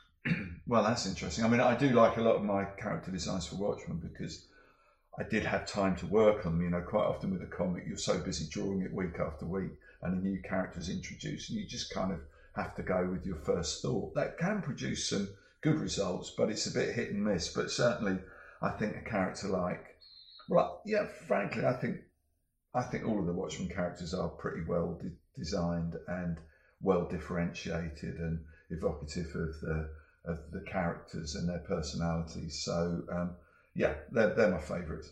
Dave Gibbons interview: Which are your favourite characters?